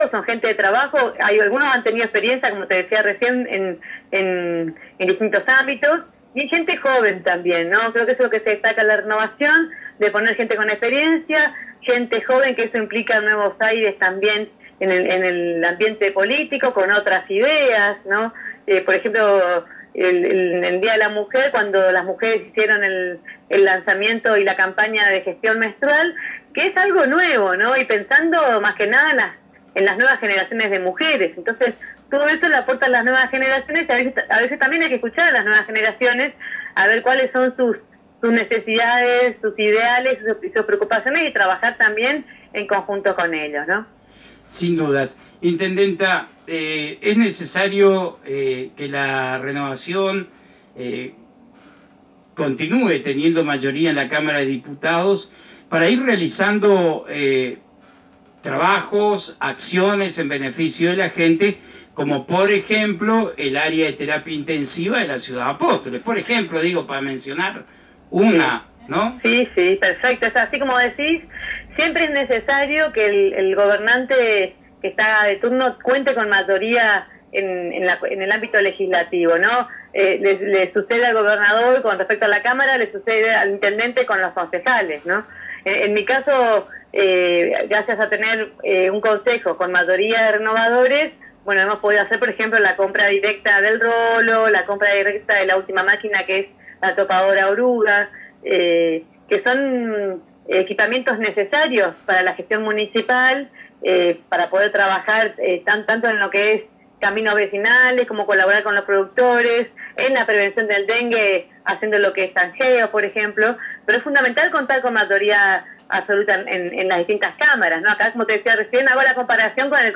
Audio: Int. Maria Eugenia Safrán